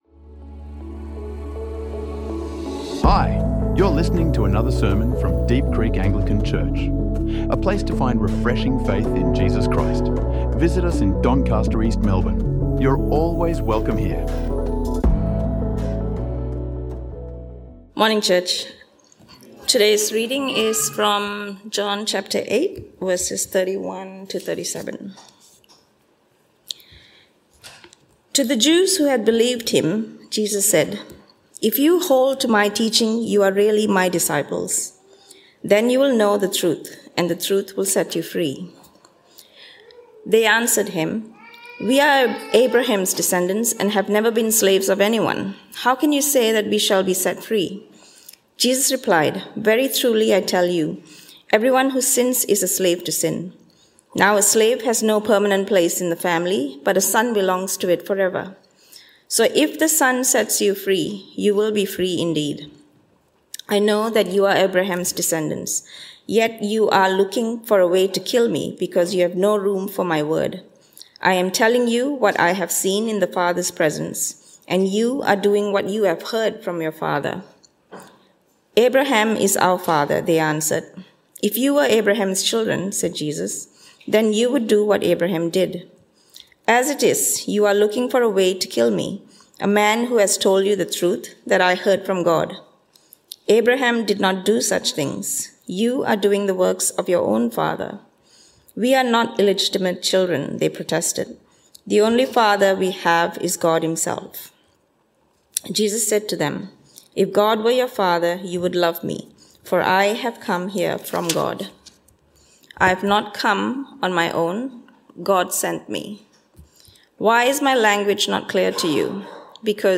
The Truth Will Set You Free | Sermons | Deep Creek Anglican Church